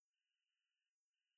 1381msPause.wav